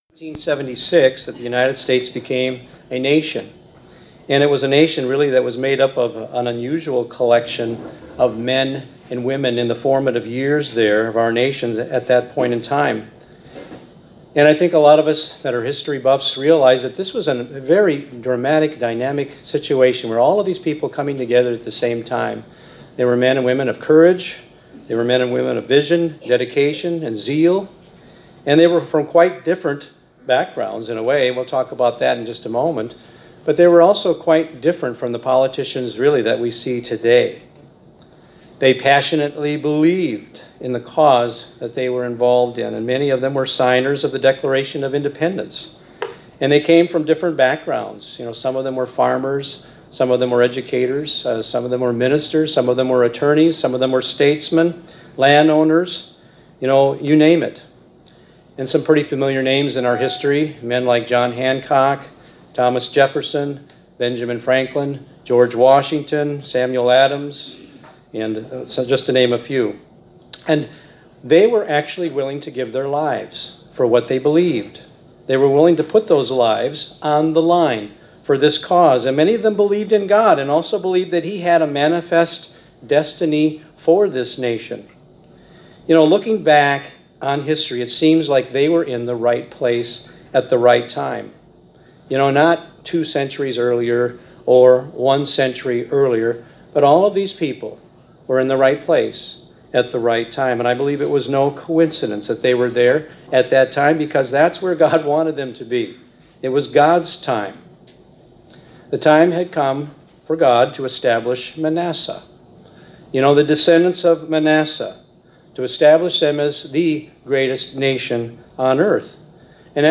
Given in La Crosse, WI